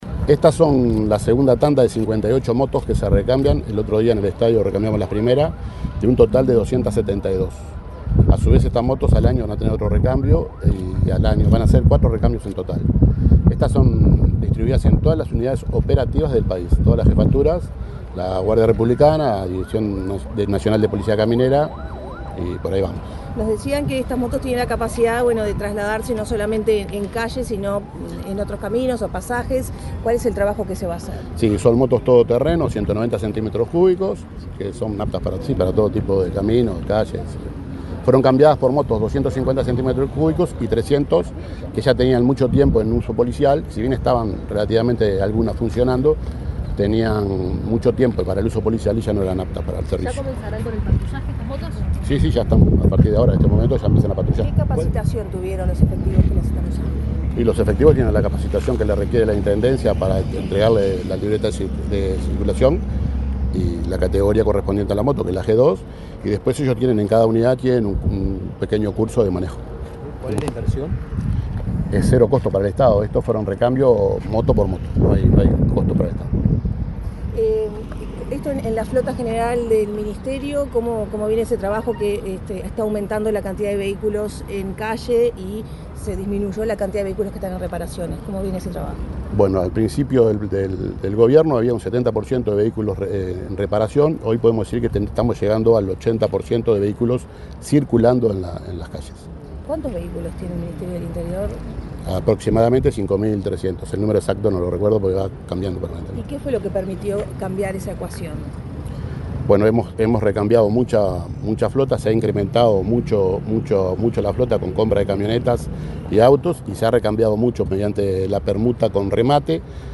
Declaraciones del director de Transporte del Ministerio del Interior, Ricardo Chinazo
Declaraciones del director de Transporte del Ministerio del Interior, Ricardo Chinazo 23/10/2024 Compartir Facebook X Copiar enlace WhatsApp LinkedIn El director de Transporte del Ministerio del Interior, Ricardo Chinazo, explicó a la prensa, las características de las 58 motos entregadas este miércoles 23 a una dependencia de esa cartera, en el barrio Cerro, en Montevideo.